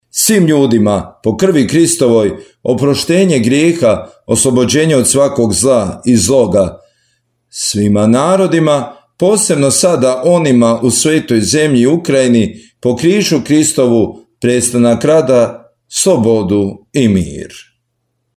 Đulovac – molitva za mir